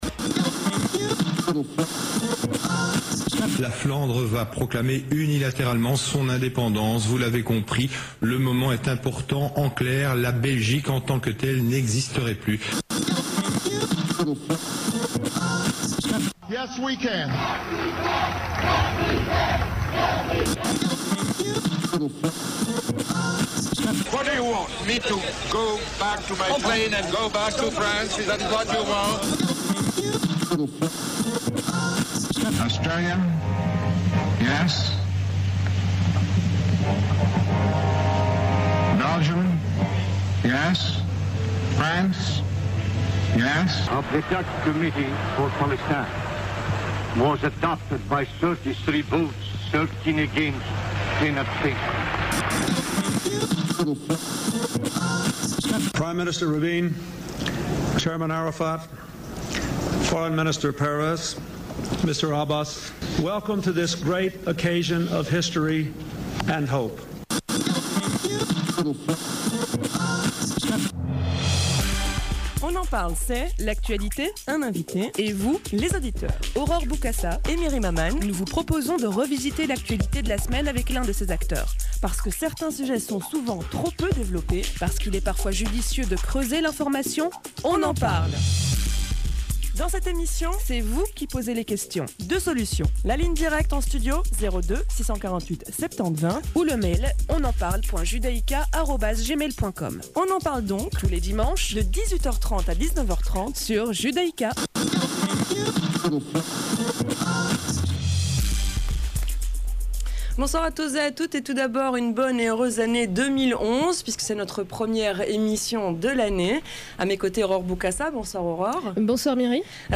Le dimanche 9 janvier, j’étais l’un des invités de l’émission « On en parle » sur Radio Judaïca. Mark Eyskens, Daniel Bacquelaine et moi-même avons débattu de la crise politique actuelle.